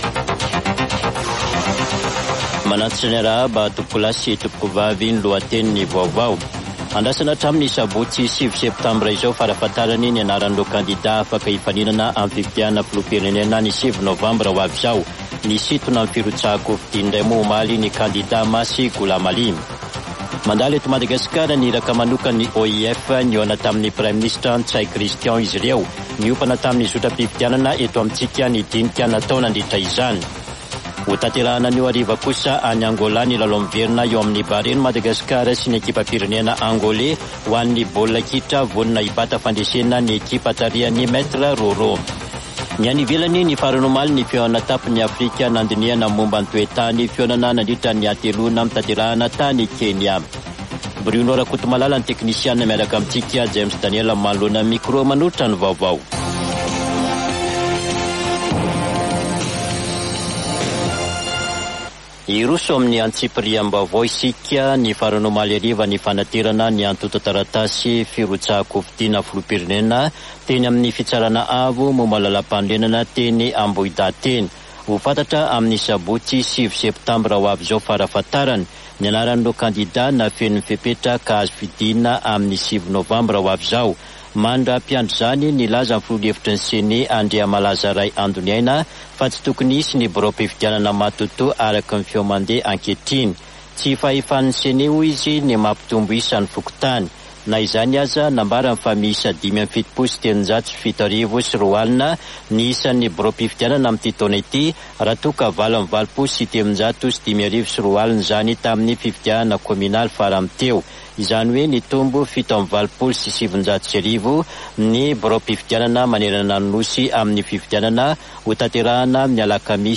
[Vaovao antoandro] Alakamisy 7 septambra 2023